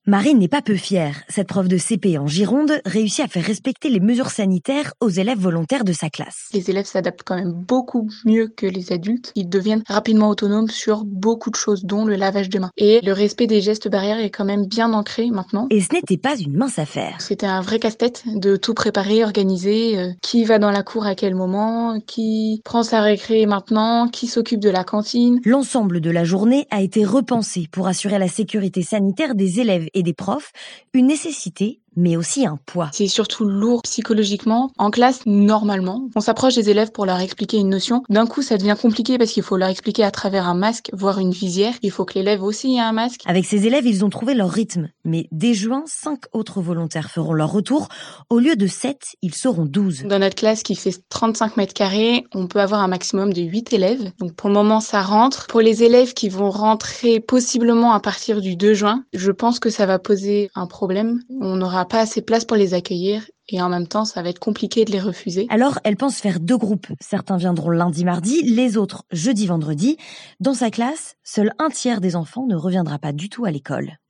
Retour sur le témoignage d'une institutrice dans une école girondine.